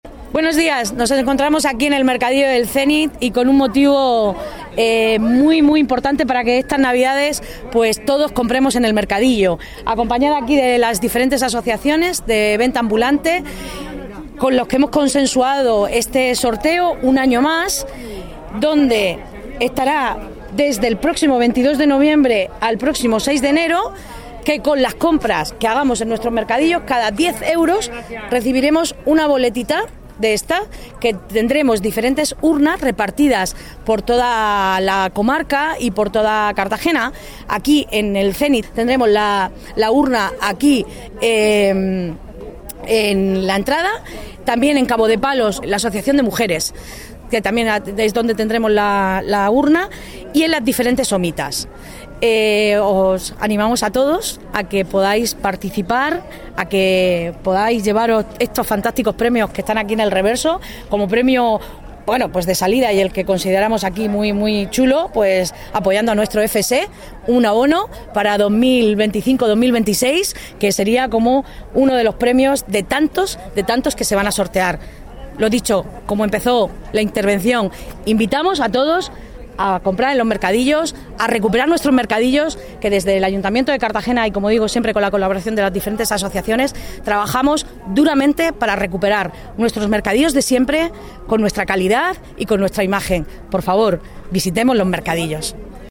Enlace a Declaraciones de la edil de Comercio, Belén Romero.